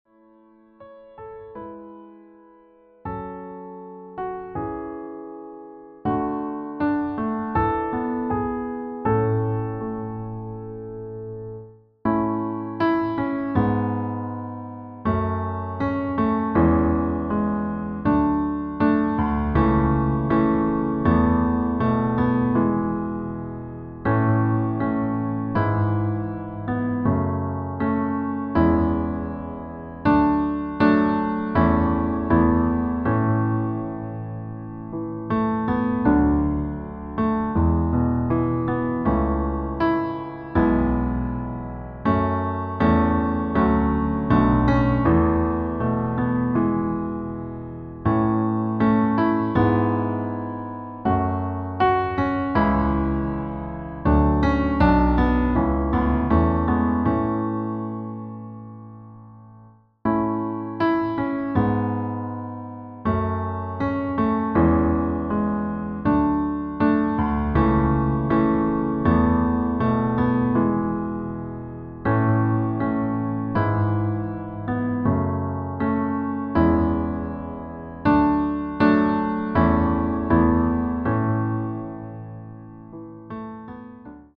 Klavierversion
Art der Einspielung Klavier / Streicher